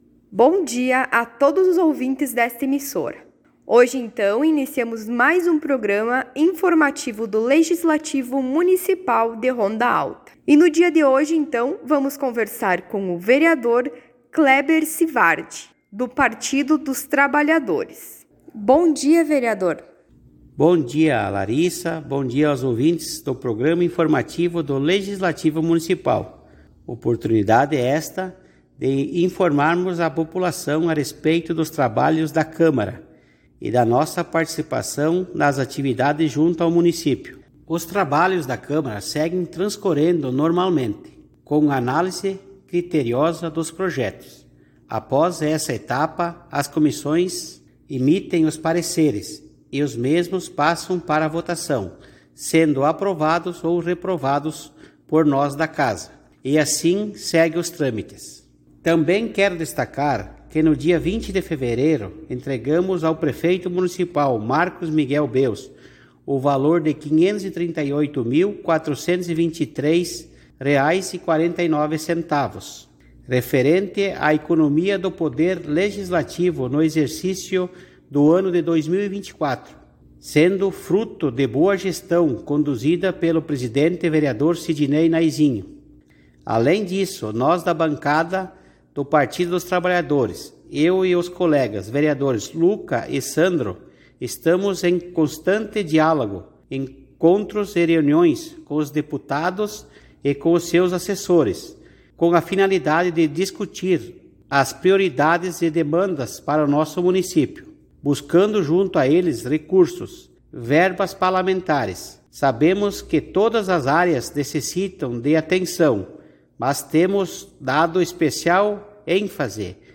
Informativos radiofônicos